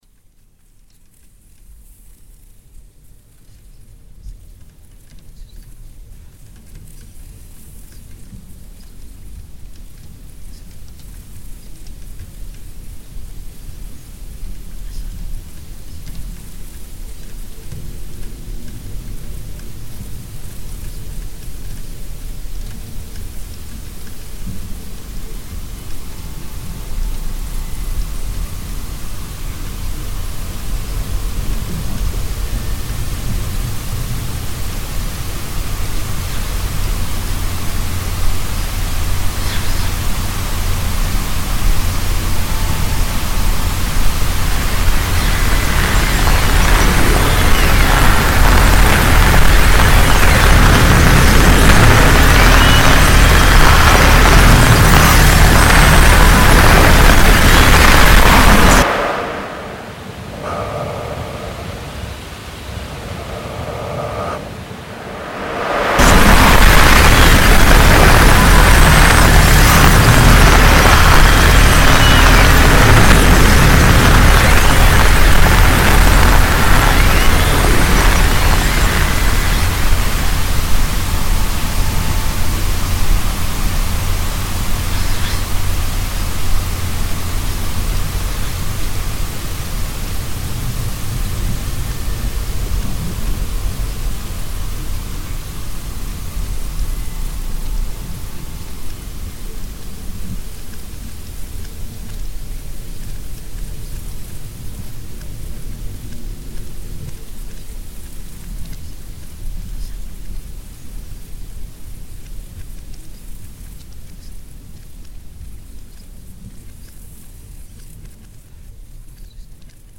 A breathing transit towards a threshold or boundary that separates and unites two sound spaces: an inhalation and an exhalation. When the inhalation ends the exhalation begins but the exhalation is just the reflection of the inhalation starting again from the end.